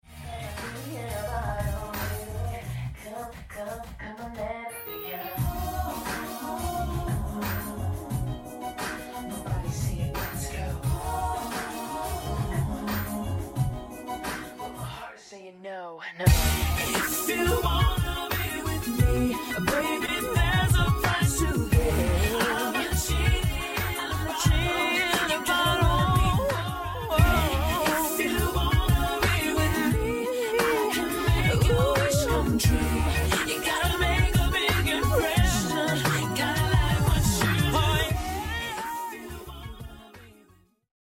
pitch and directional